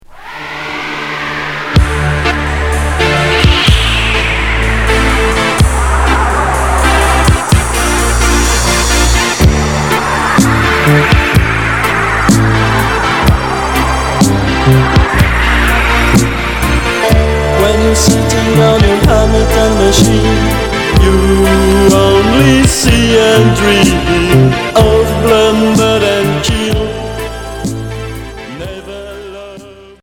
Rock FM Unique 45t retour à l'accueil